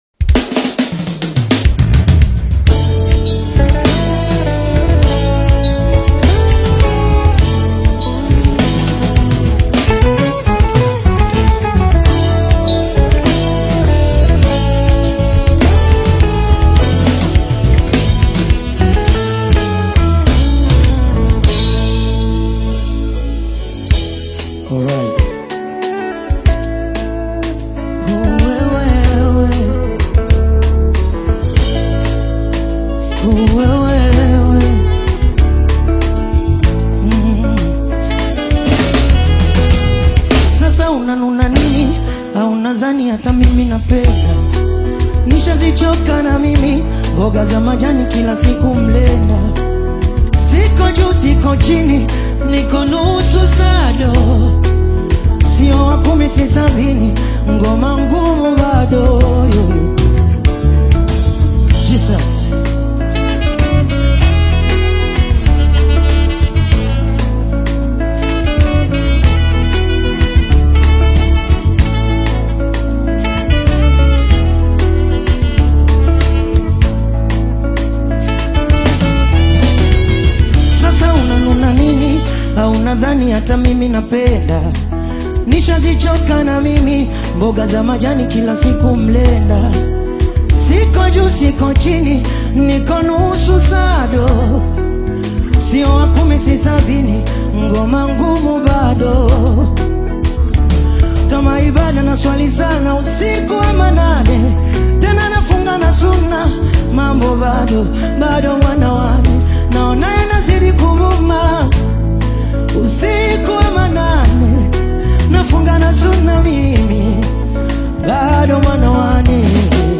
Afro-Pop/Bongo Flava
uplifting melodies
emotive vocal style
Live performance